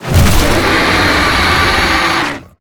Sfx_creature_chelicerate_roar_03.ogg